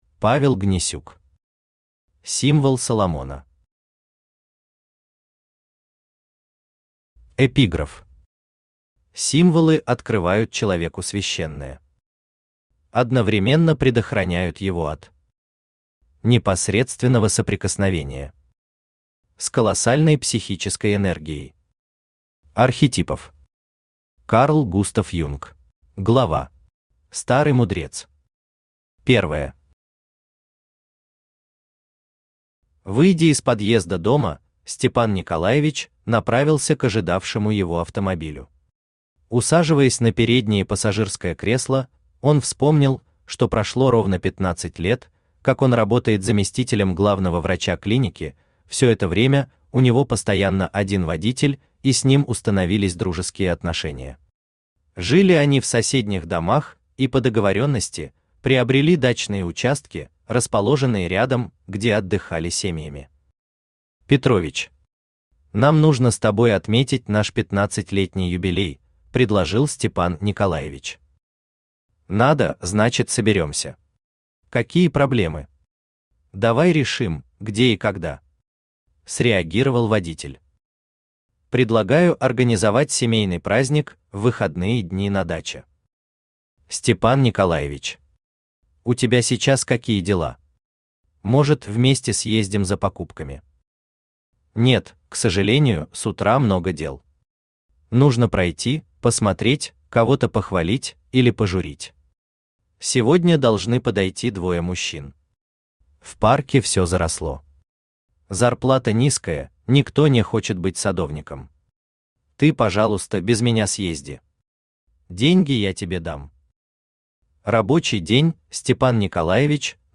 Аудиокнига Символ Соломона | Библиотека аудиокниг
Aудиокнига Символ Соломона Автор Павел Борисович Гнесюк Читает аудиокнигу Авточтец ЛитРес.